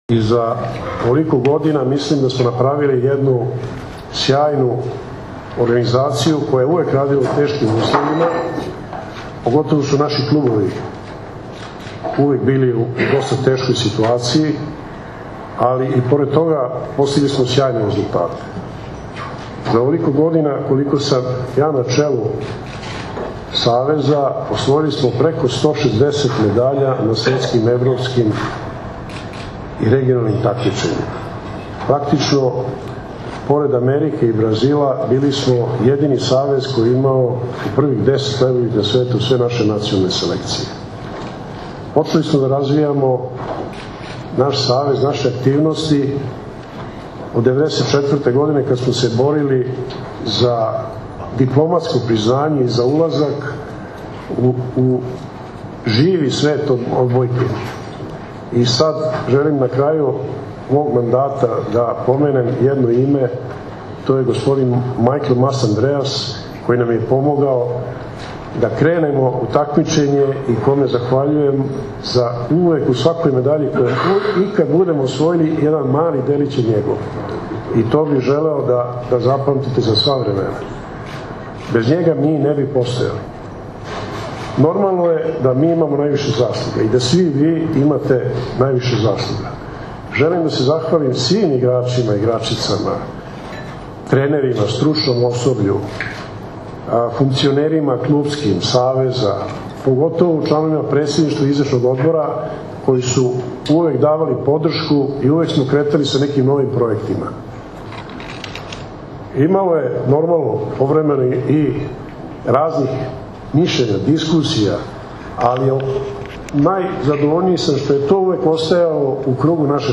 ODBOJKAŠKI SAVEZ SRBIJE – IZBORNA SKUPŠTINA
Izborna Skupština Odbojkaškog saveza Srbije održana je danas u beogradskom hotelu „M”, a pravo glasa imalo je 47 delegata.